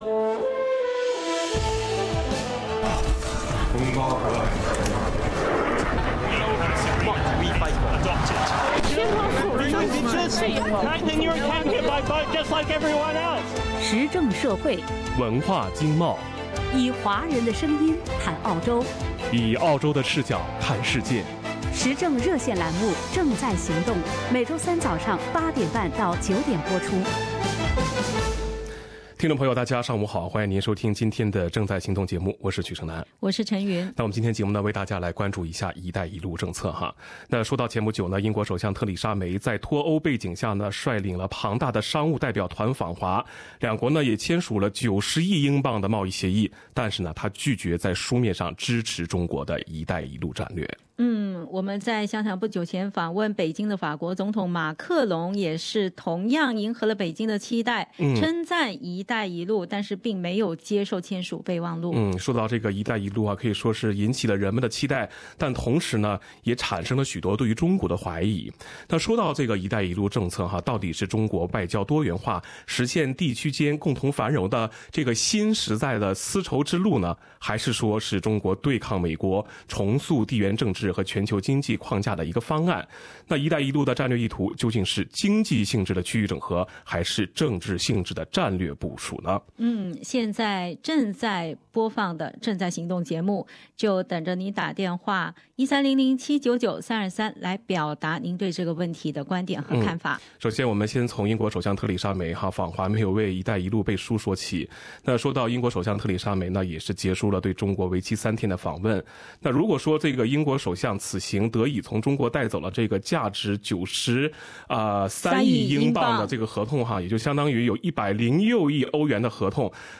一带一路的战略意图究竟是经济性质的区域整合，还是政治性质的战略部署？ 本期《正在行动》节目，听众热议“一带一路，一条共存共荣的新丝绸之路，还是一厢情愿的单行线”。